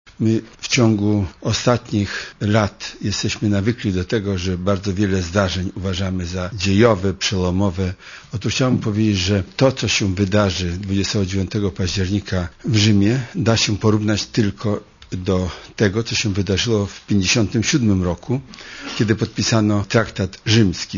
To będzie jedna z ważniejszych chwil w historii Europy - mówi wiceminister spraw zagranicznych Adam Daniel Rotfeld
Komentarz audio